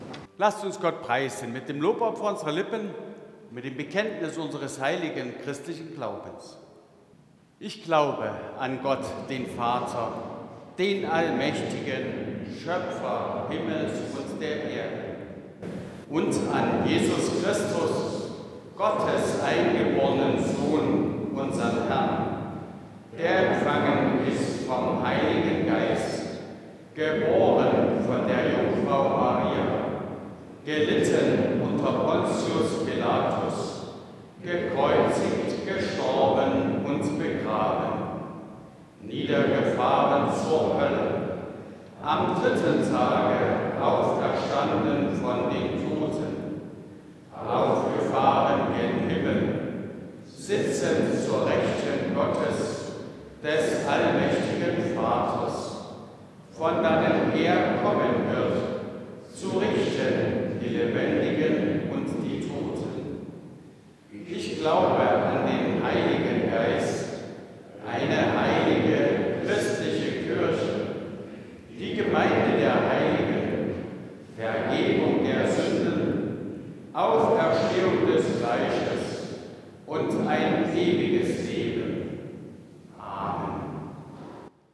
8. Apostolisches Glaubensbekenntnis Ev.-Luth.
Audiomitschnitt unseres Gottesdienstes vom 2. Sonntag nach Epipanias 2026.